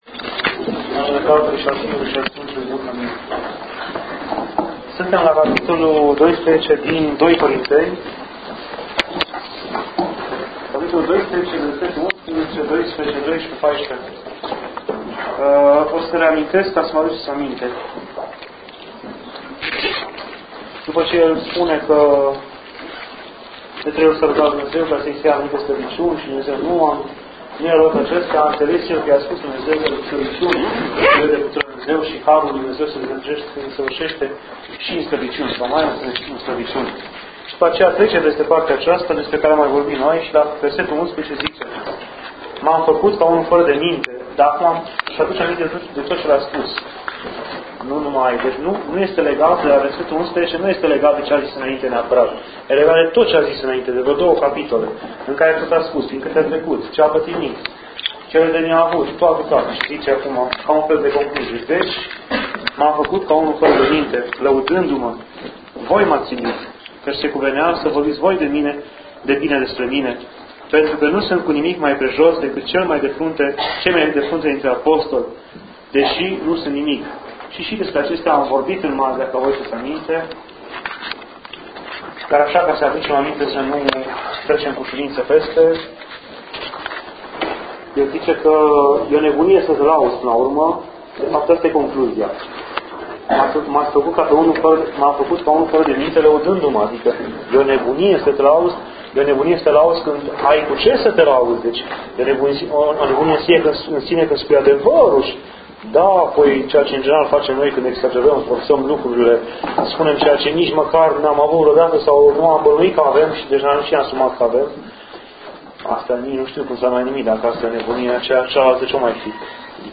Aici puteți asculta și descărca înregistrări doar de la Bisericuța din Hașdeu